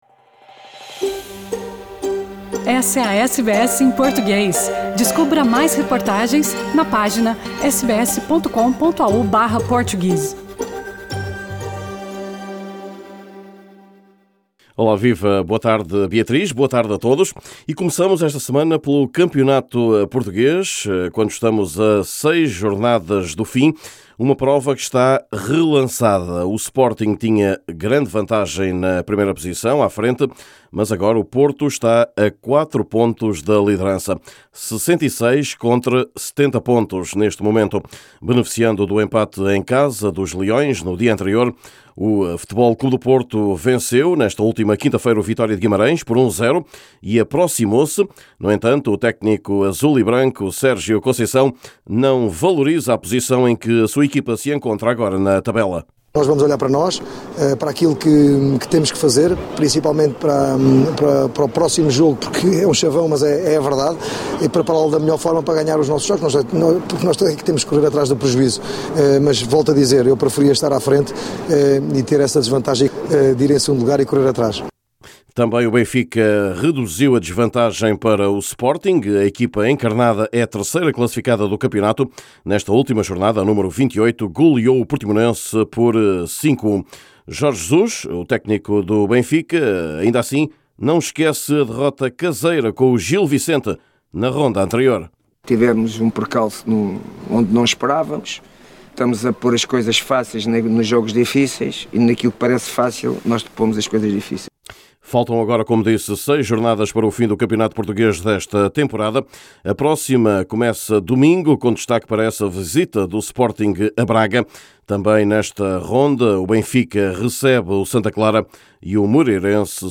Nesse boletim esportivo: Sporting perdeu vantagem larga e FC Porto está agora a quatro pontos da liderança, quando faltam jogar seis rondas da prova lusa.